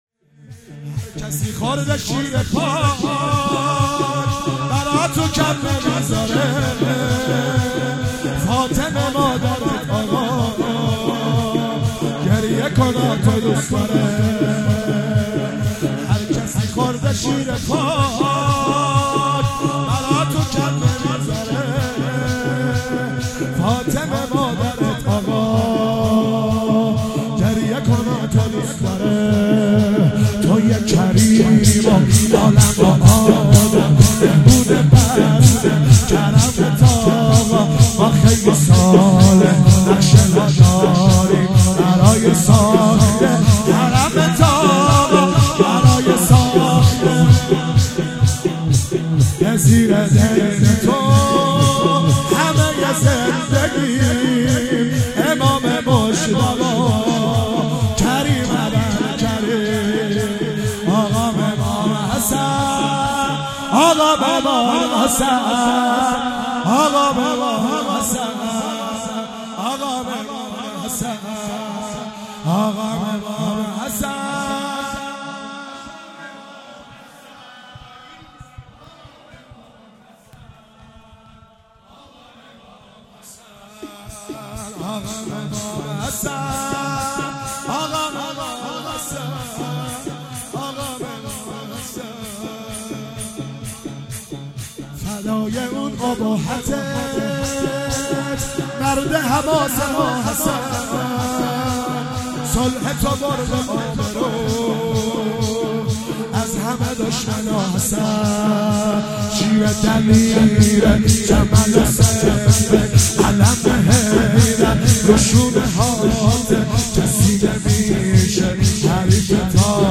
شور - هر کسی خورده شیر پاک